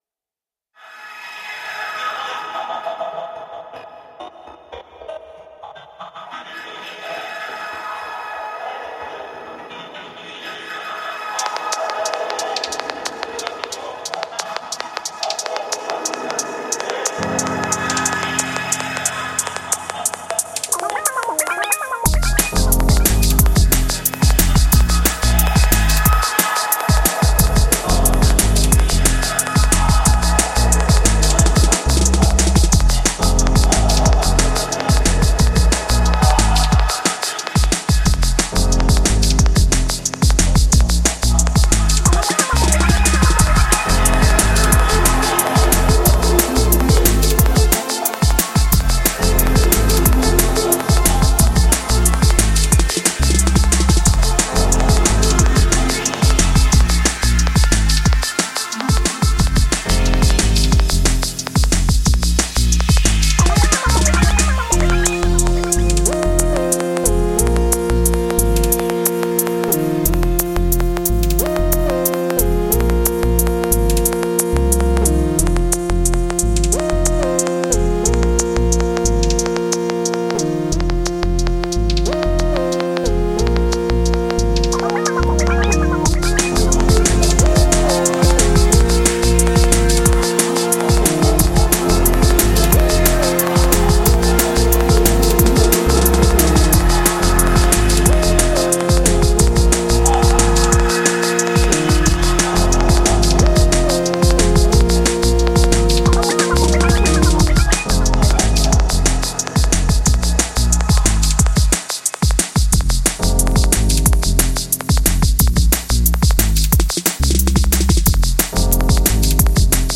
I added drums…